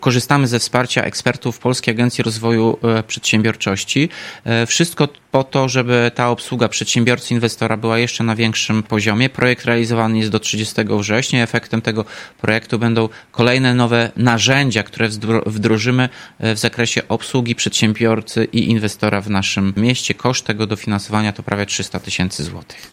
– Wszystko po to, żeby przedsiębiorca, który zgłosi się do urzędu lub do Parku Naukowo-Technologicznego, mógł uzyskać najlepszą możliwą pomoc, ale także wsparcie specjalistów – mówi Tomasz Andrukiewicz, prezydent Ełku.